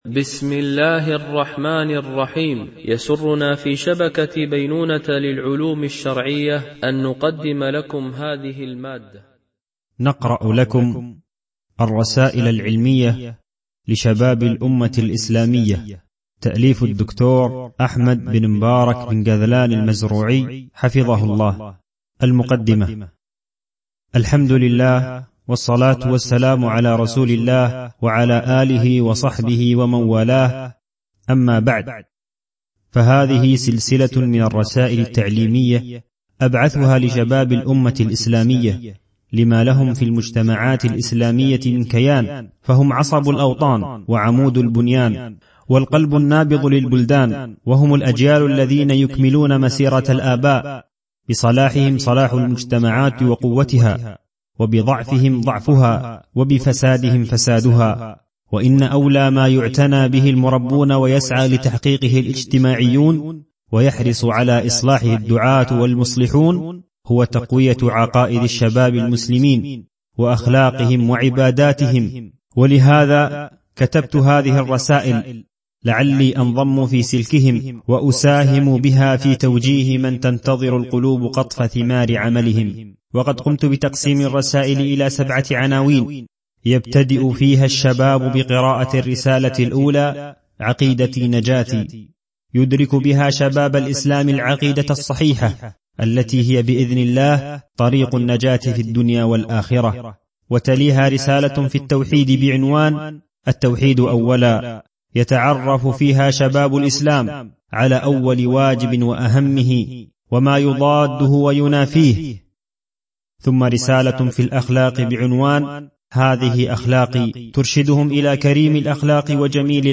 audiobook by title - | شبكة بينونة للعلوم الشرعية